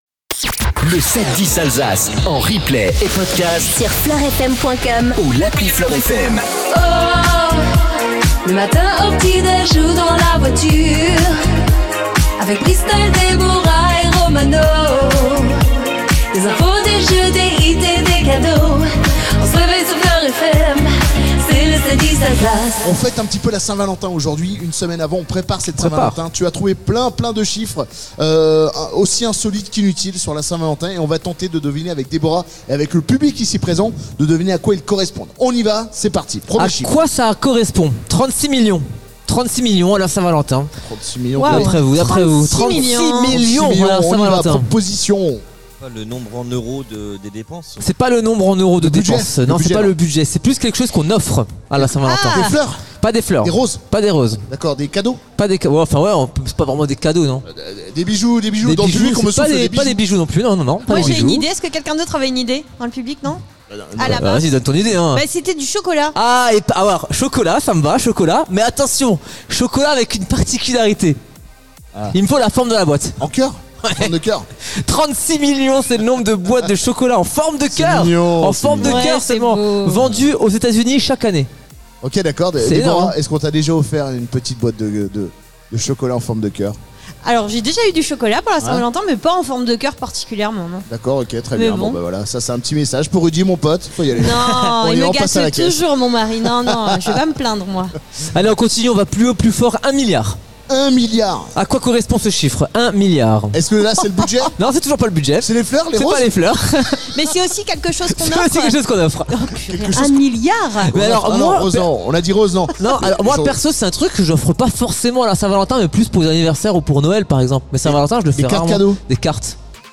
en direct du Centre Commercial Île Napoléon Illzach